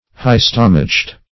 Search Result for " high-stomached" : The Collaborative International Dictionary of English v.0.48: High-stomached \High"-stom`ached\, a. Having a lofty spirit; haughty.